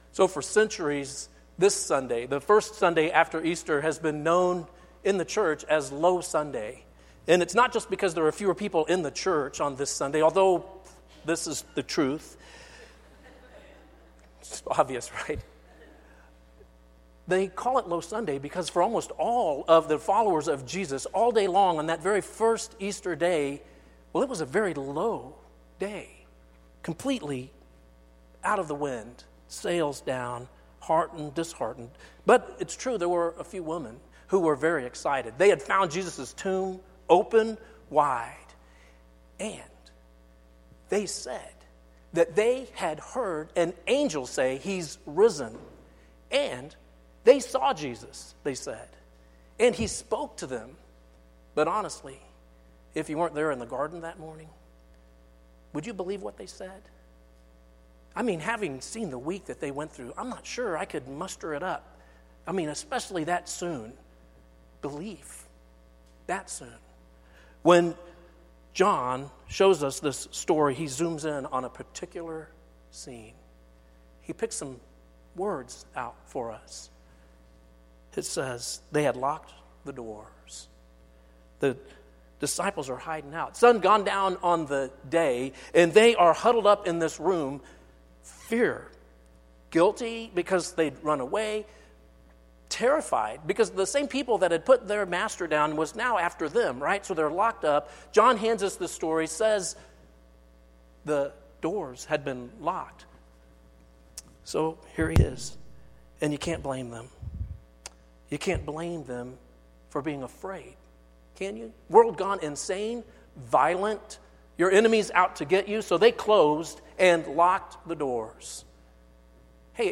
They Had Locked The Doors (Sermon) - The Church @ Highland Park
they_had_locked_the_doors_sermon_4_23_17.mp3